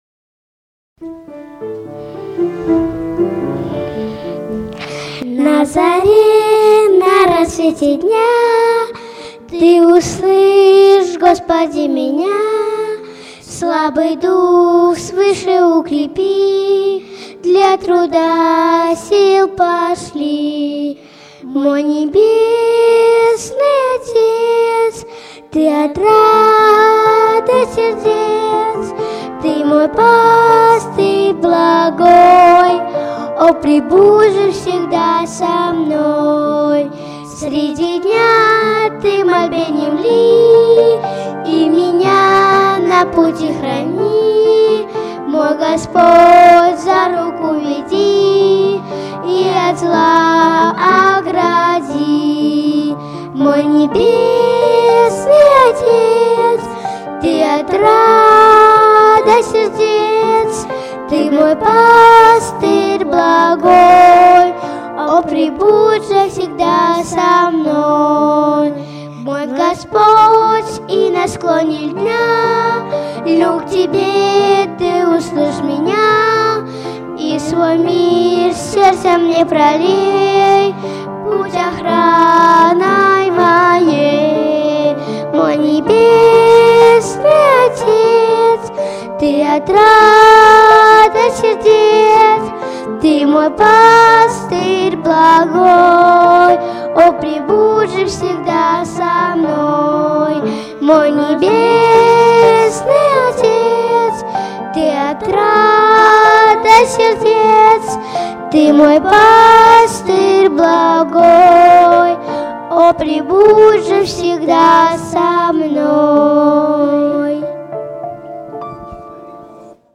18-12-16 / На заре, на рассвете дня (Детское прославление)